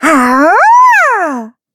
Taily-Vox_Attack6_kr.wav